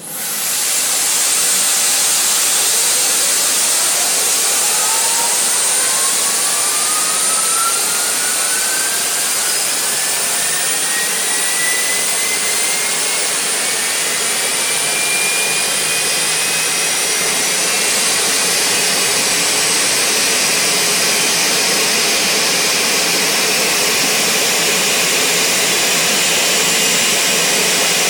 x320_cfm_starter.wav